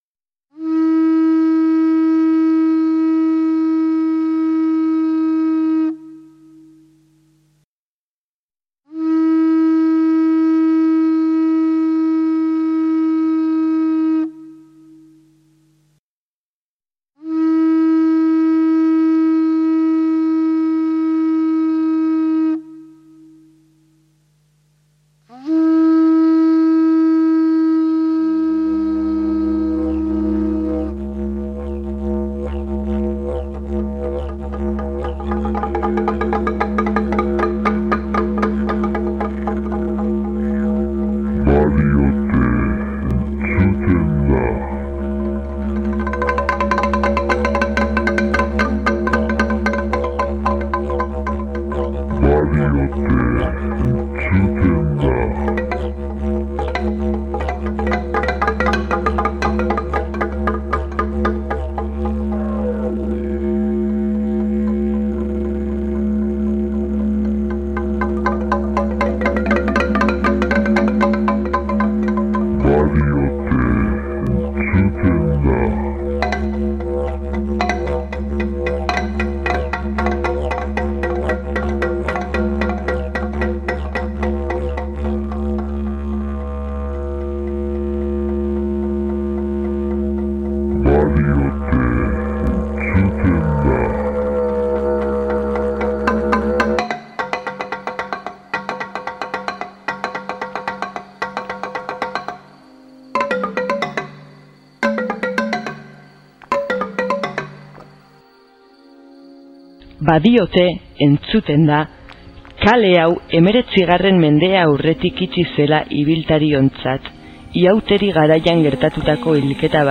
KASKABOBO ETA MASKARITEN KALEA IREKI BAINO LEHEN SOINUA: